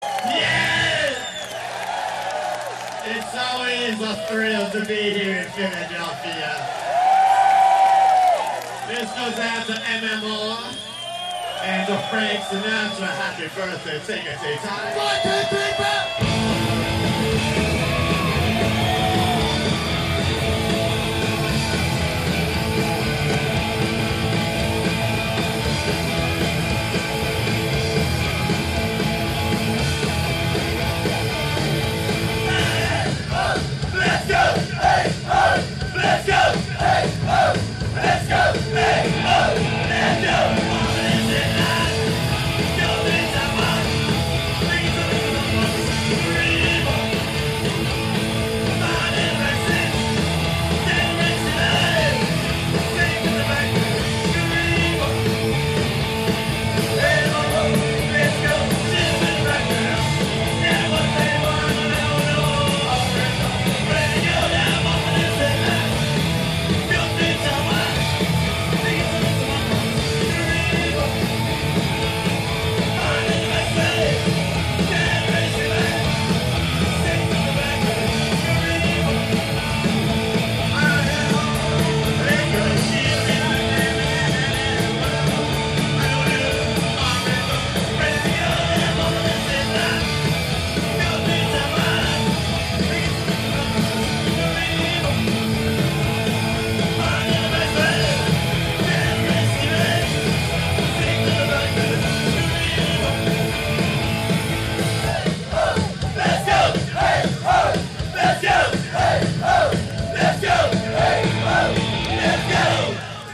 Chestnut Cabaret Philadelphia 12-13-87